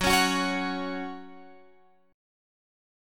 F#5 chord {14 16 16 x 14 14} chord